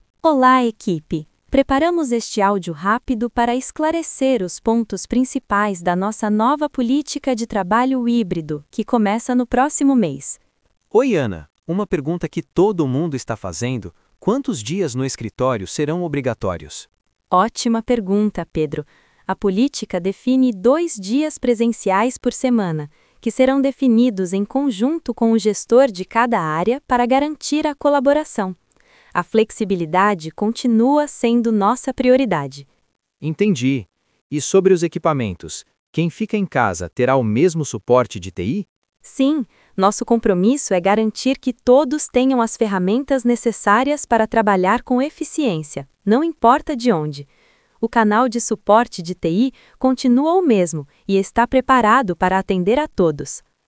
Ele transforma um roteiro em um episódio completo de áudio, com múltiplas vozes, diálogos e longa duração — sem microfone, sem edição manual e sem software de áudio.
• gera um único arquivo de áudio coeso, simulando um programa de podcast com múltiplos participantes